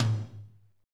TOM M R H08L.wav